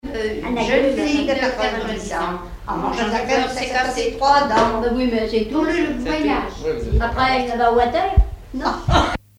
enregistré dans le dernier café d'Honfleur où il n'y avait pas de touristes
Enfantines - rondes et jeux
Pièce musicale inédite